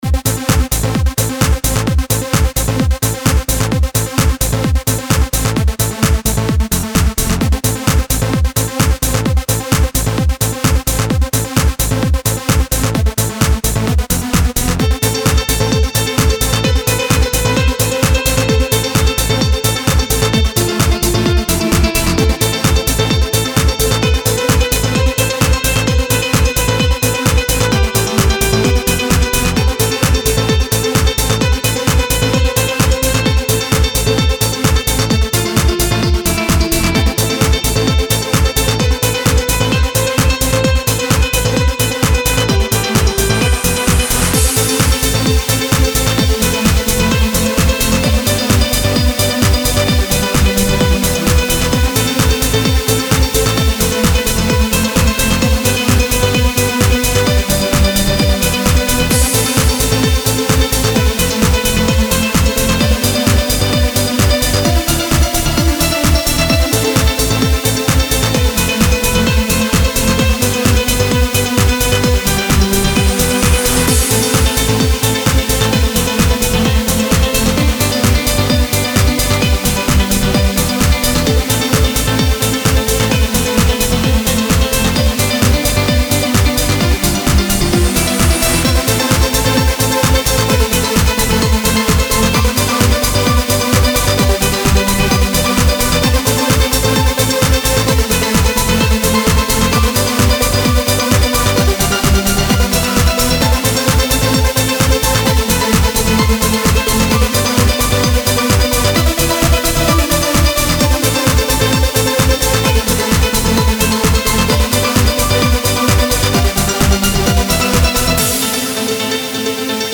для написания всех треках использовался плагин Nexuse